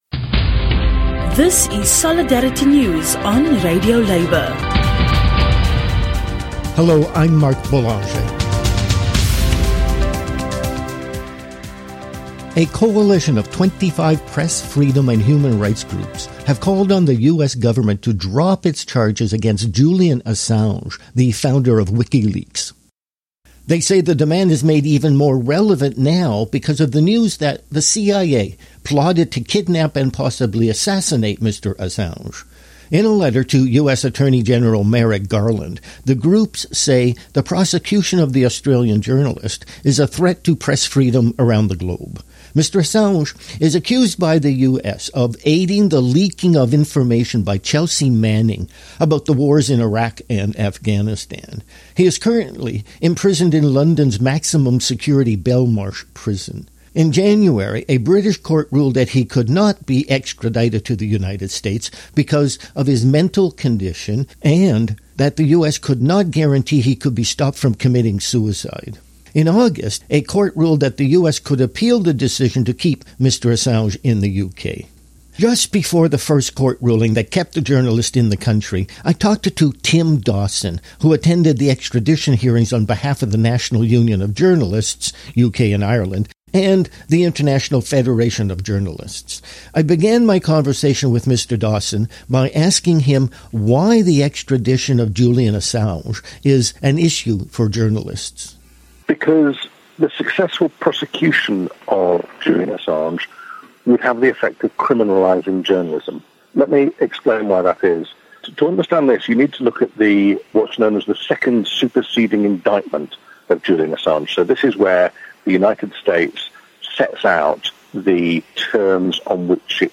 Efforts to stop the extradition of Julian Assange to the US intensifies after news that the CIA planned to kidnap him. An interview